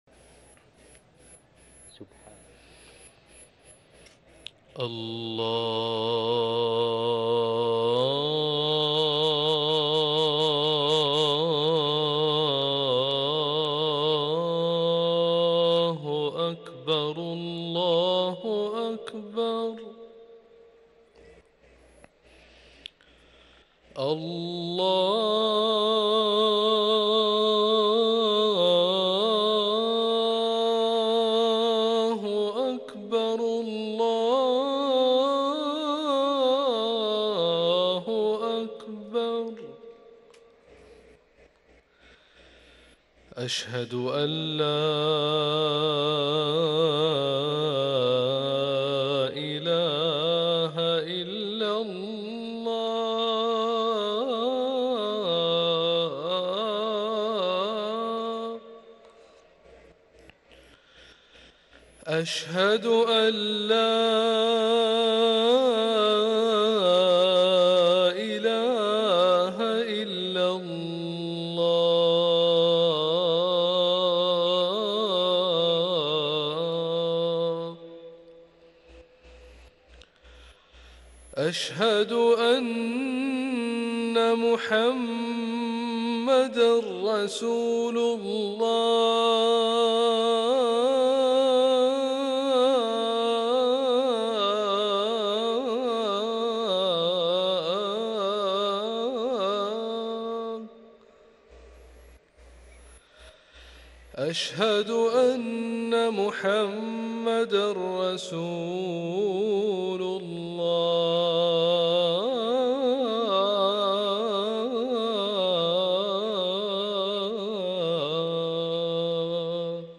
أذان العصر